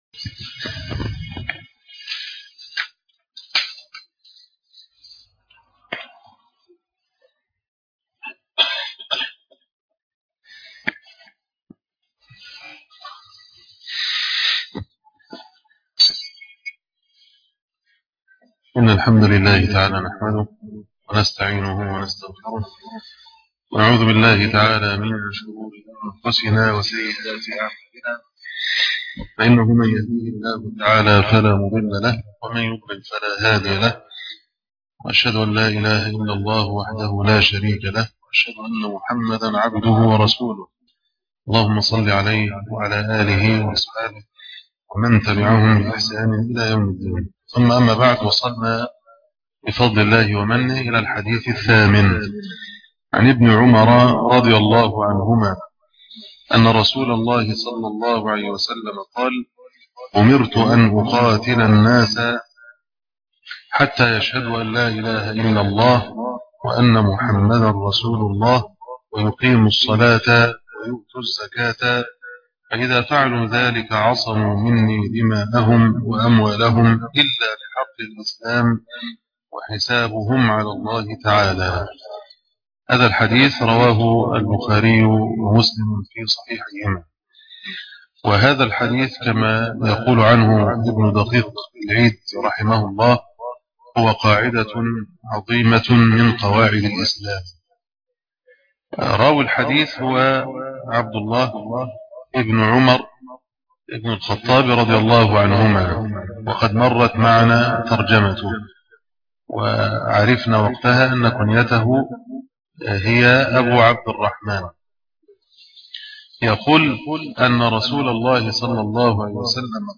شرح الأربعين النووية 8 - معهد ابن تيمية الفرقة التمهيدية - الشيخ أبو إسحاق الحويني